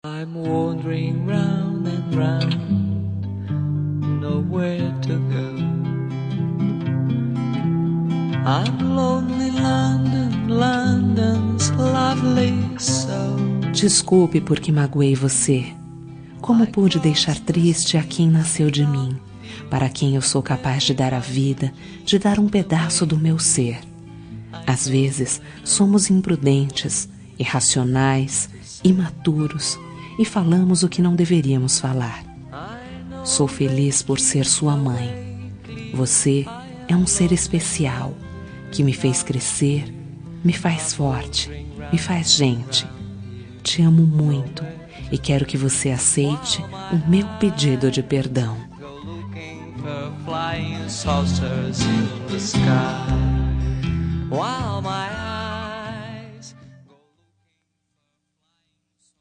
Reconciliação Familiar – Voz Feminina – Cód: 088726 – Filha (o)